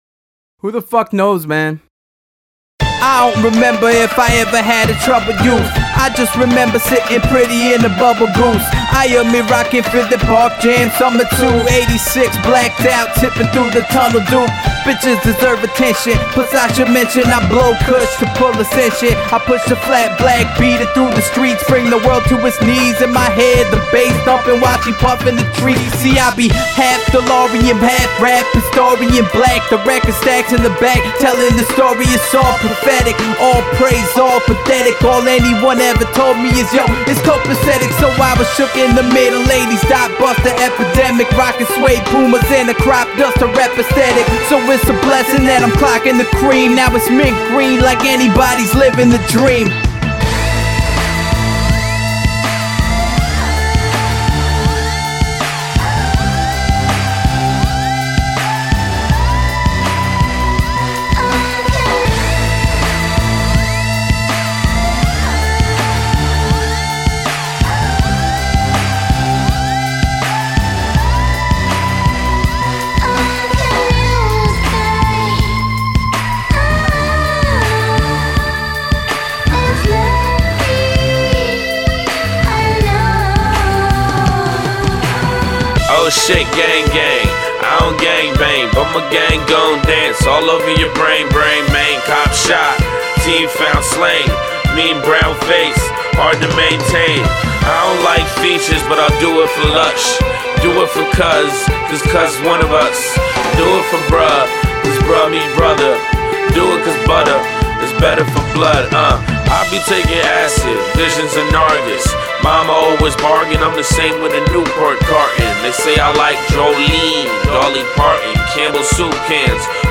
the Philadelphia rapper
provocative, unexpected beats and fast rhymes